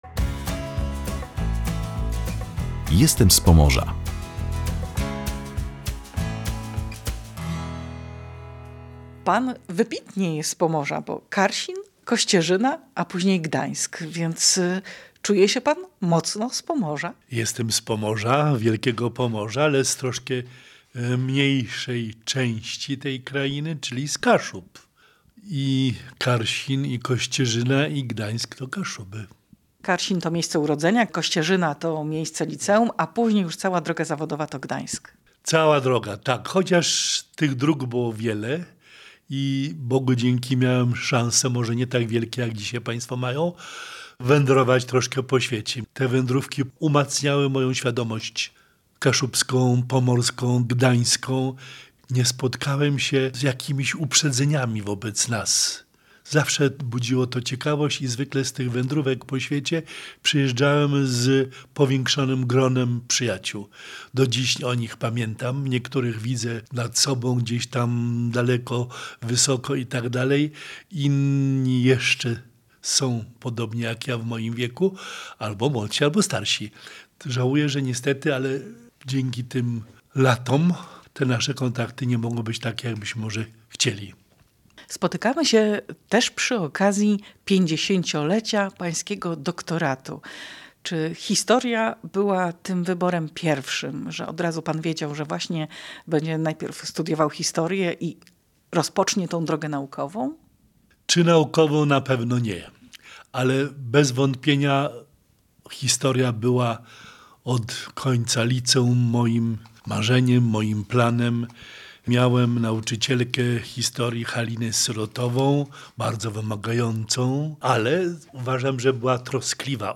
Z prof. Józefem Borzyszkowskim rozmawiała